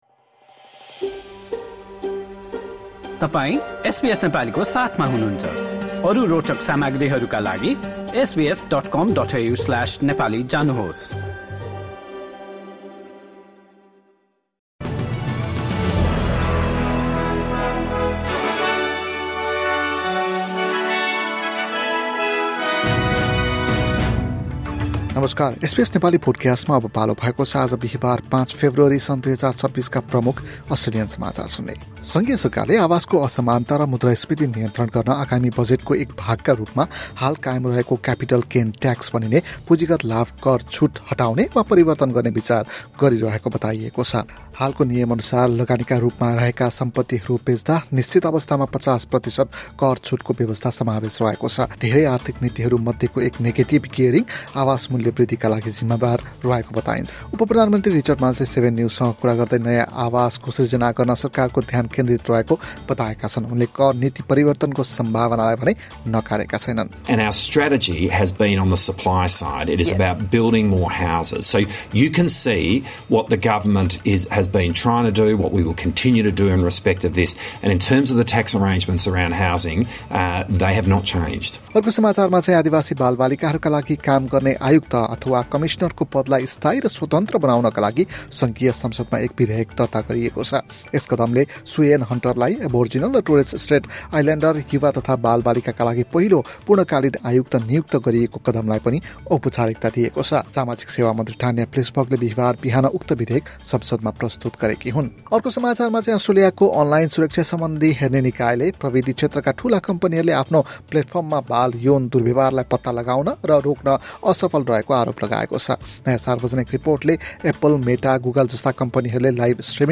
एसबीएस नेपाली प्रमुख अस्ट्रेलियन समाचार: बिहीवार, ५ फेब्रुअरी २०२६